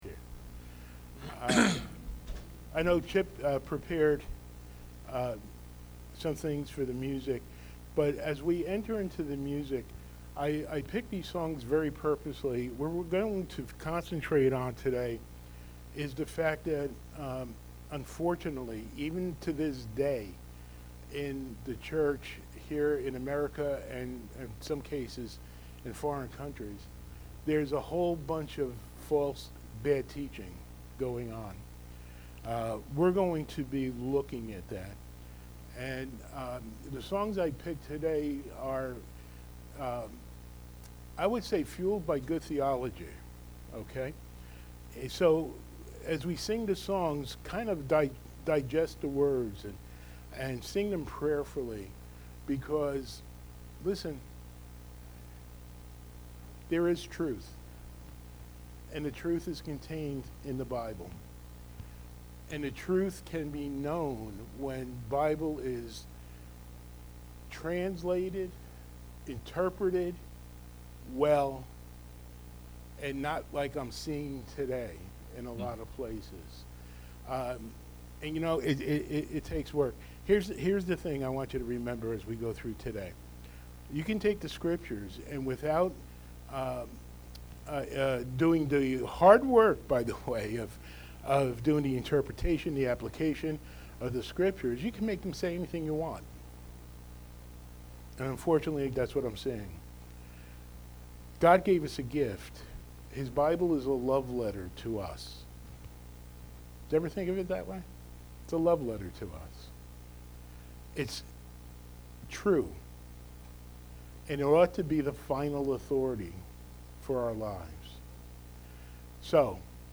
Podcast (sermons): Play in new window | Download